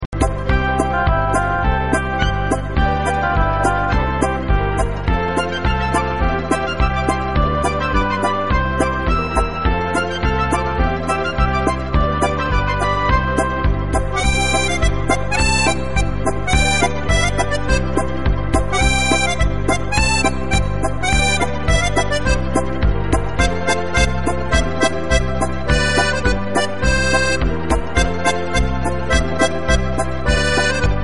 * В качестве музыкального фрагмента можно использовать мелодию "Пчелочка златая".